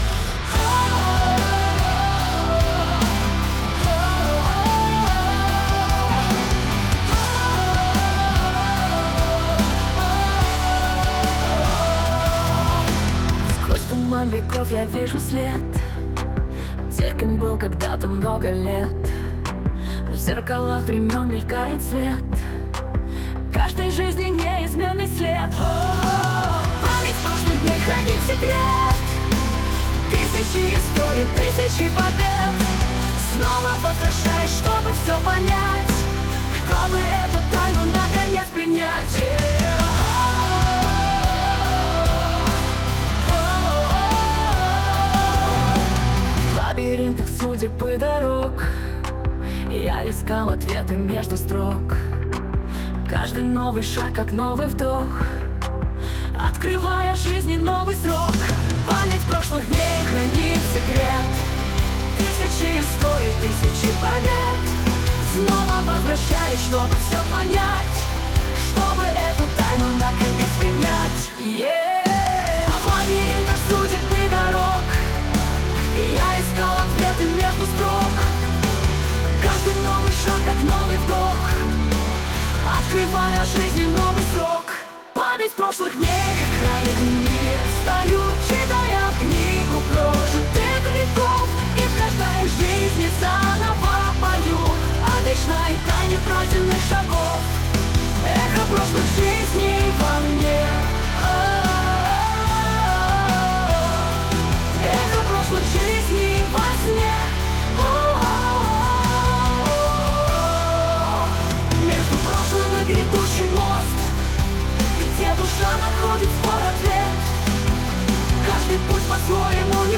Жанр: Progressive rock